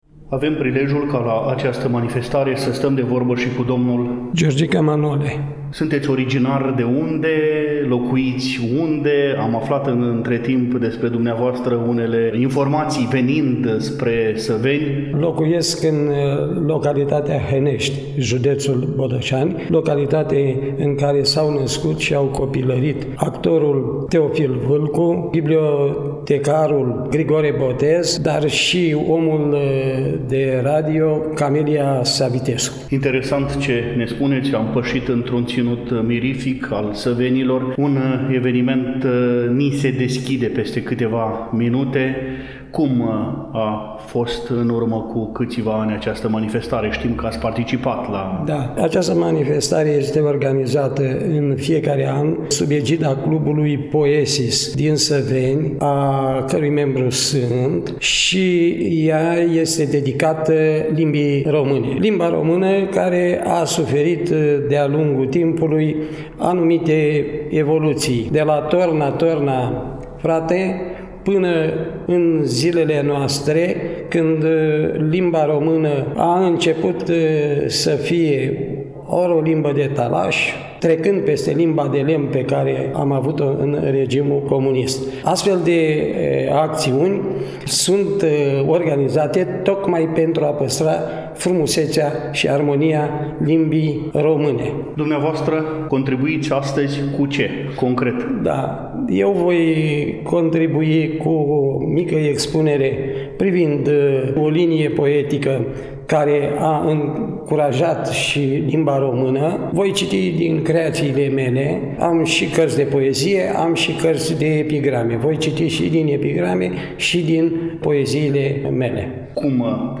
După cum bine știți, relatăm de la Festivalul de cultură și artă „Limba Română – Tezaur al neamului Românesc”, manifestare desfășurată cu prilejul Zilei Limbii Române, 31 august 2025, în amfiteatrul Liceului Teoretic „Dr. Mihai Ciucă” din orașul Săveni, județul Botoșani.